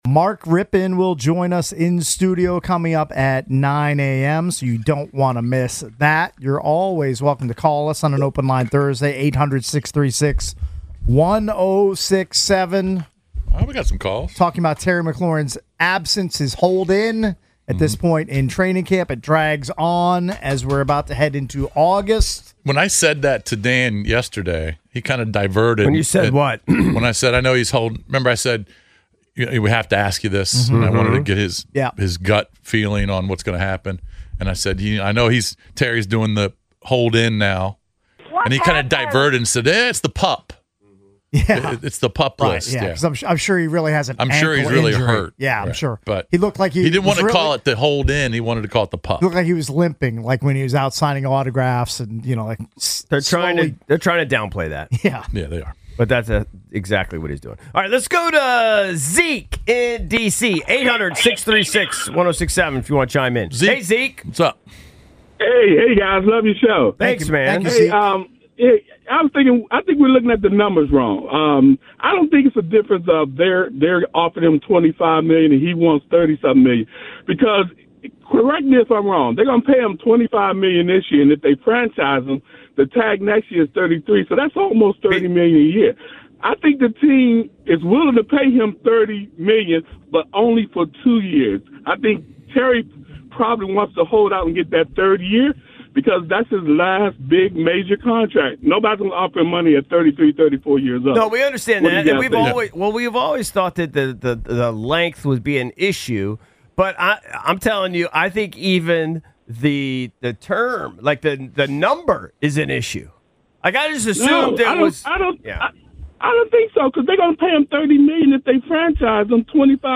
Calls On Terry McLaurin's Value The Sports Junkies Audacy Sports, Comedy 4.6 • 1.6K Ratings 🗓 31 July 2025 ⏱ 12 minutes 🔗 Recording | iTunes | RSS 🧾 Download transcript Summary From 07/31 Hour 3: The Sports Junkies discuss Terry McLaurin's value with callers.